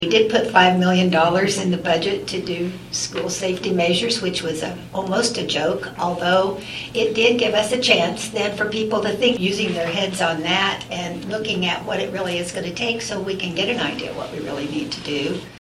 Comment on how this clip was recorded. MANHATTAN — The Manhattan-Ogden USD 383 Board of Education held a special work session Wednesday with Kansas lawmakers regarding some of the issues they hope to see brought up in the upcoming legislative session.